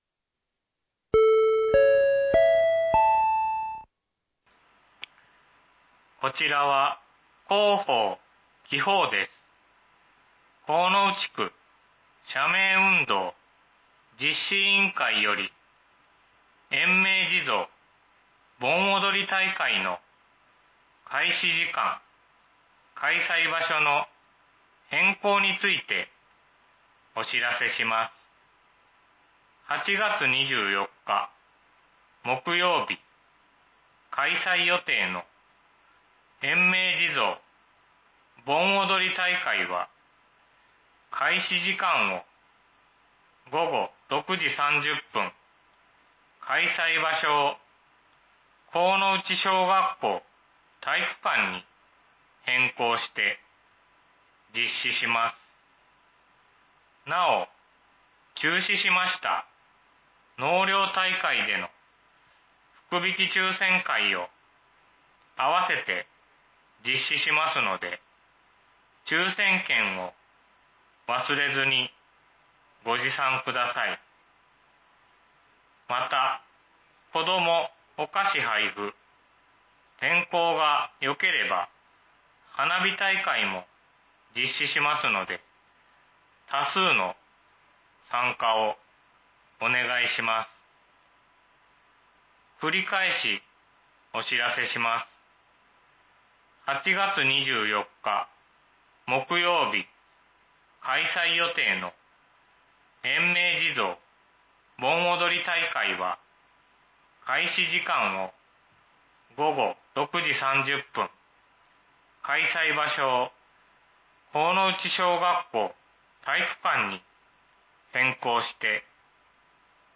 （神内地区のみの放送です。）
放送音声